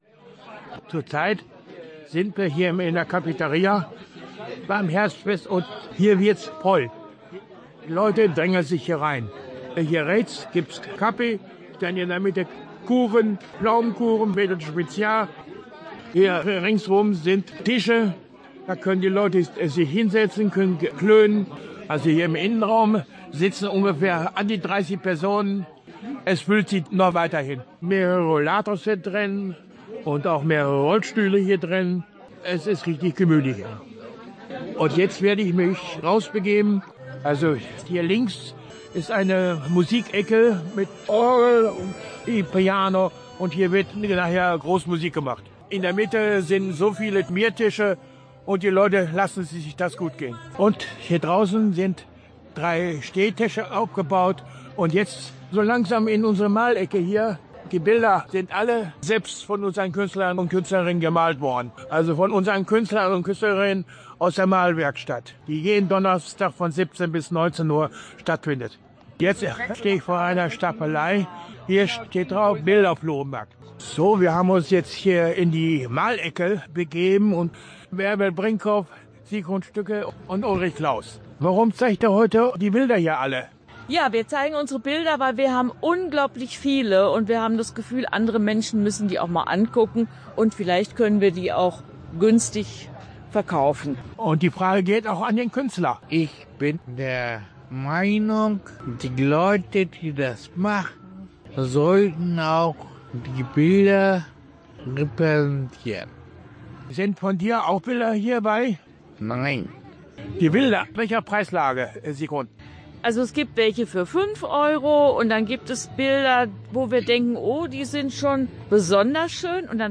Herbstfest Begegnungszentrum Bültmannshof